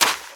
STEPS Sand, Run 25.wav